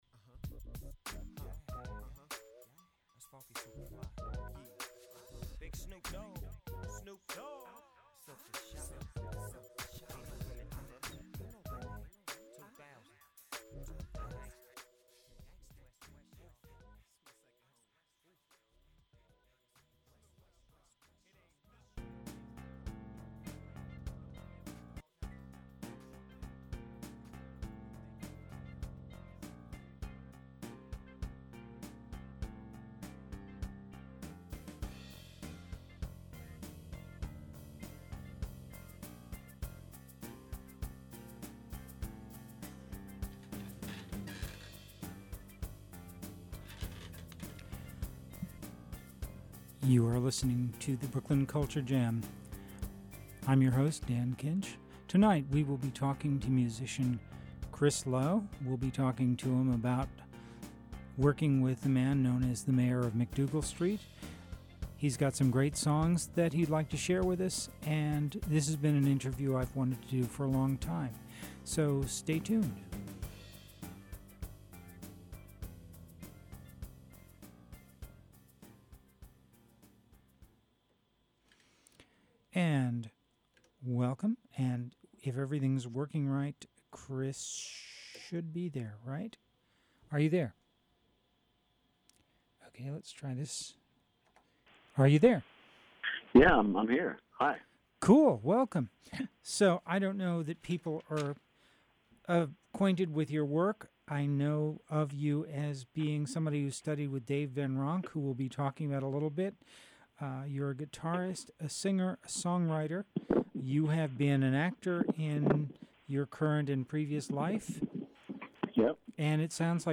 We got to listen to some of his songs, and talk about whether Greenwich Village is ever what it used to be. We talked about his songs and heard performances of some recent work. It was a fun interview, and I’m a big fan.